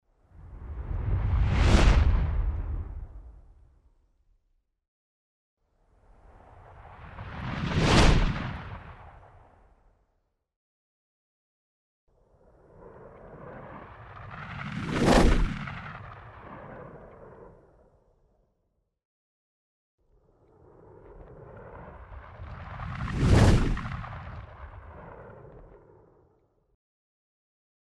Swoosh, movimiento rápido, Espacio | efecto de sonido .mp3 | Descargar gratis.
Espacio, movimiento rápido, swoosh: